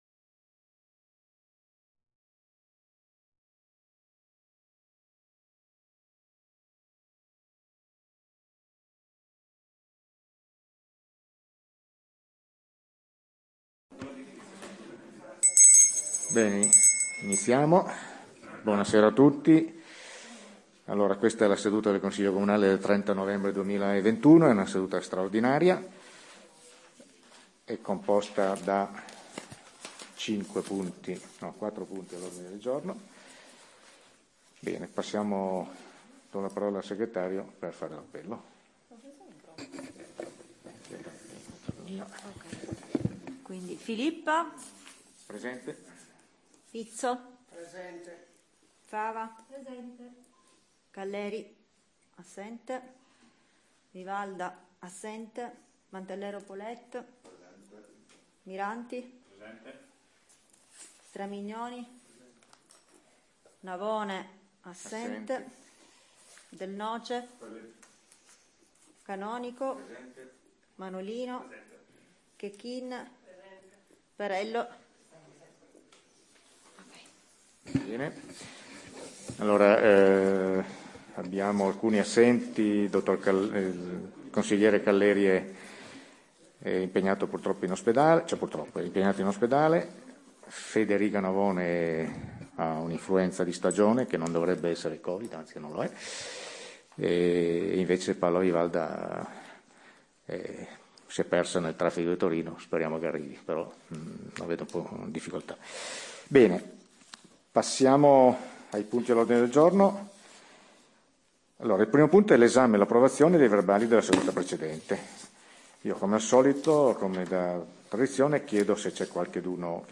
Registrazione Consiglio comunale Comune di Pecetto Torinese